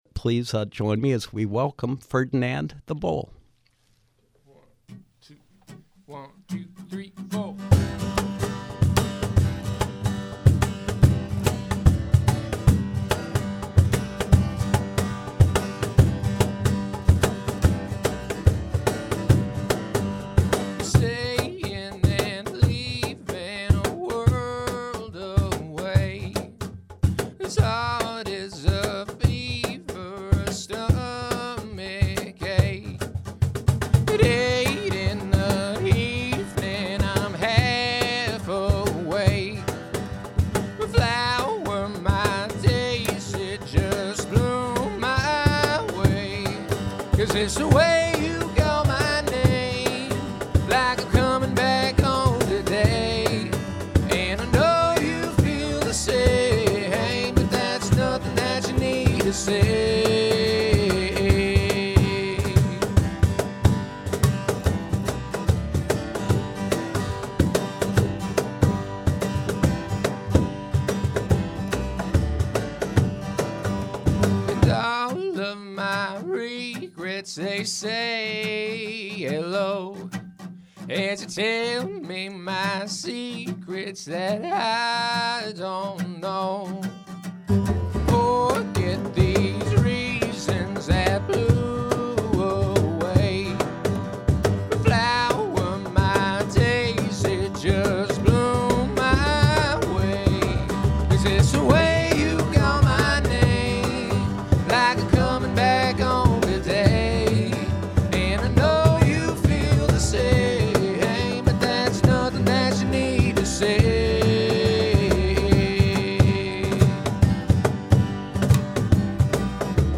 indie/folk band